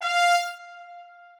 strings2_26.ogg